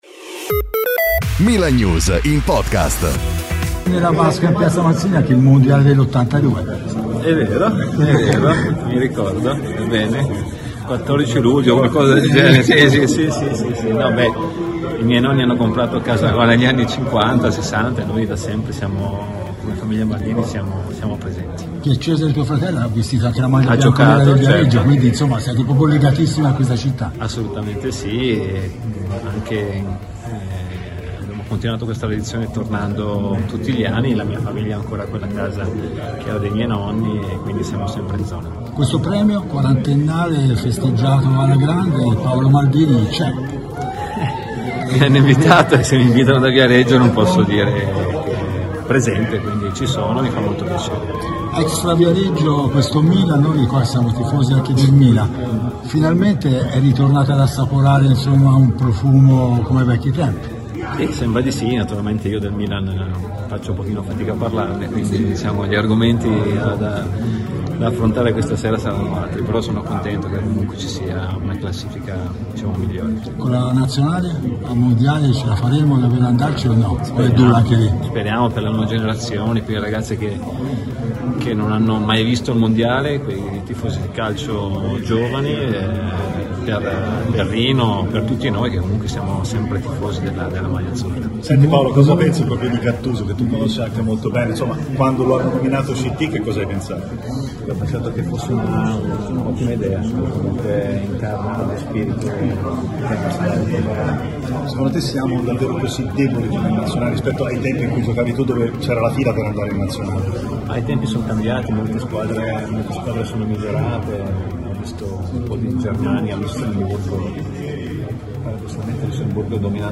L'ex capitano e dirigente rossonero Paolo Maldini è stato intervistato a margine del 40° Premio Viareggio di cui sarà ospite questa sera. Tra i vari temi toccati, inevitabilmente anche il Milan, il derby di domenica sera e l'ex tecnico del Diavolo Stefano Pioli.